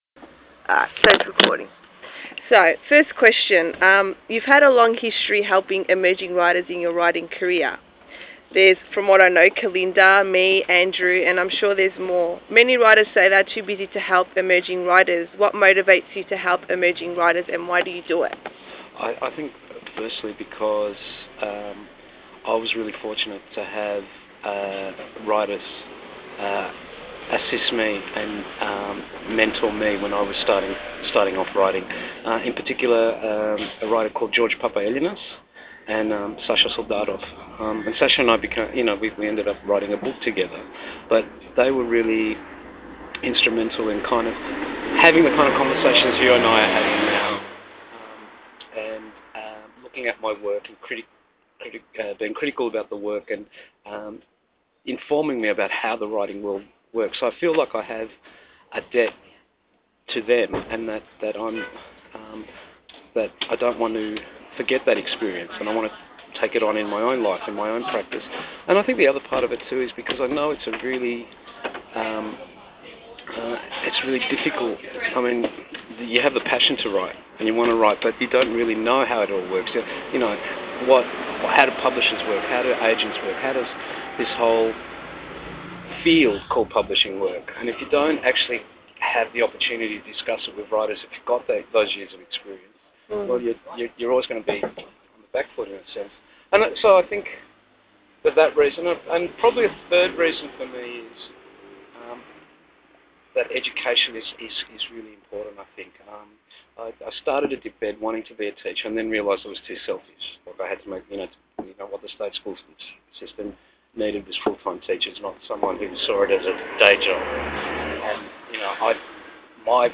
Christos Tsiolkas interview (2009)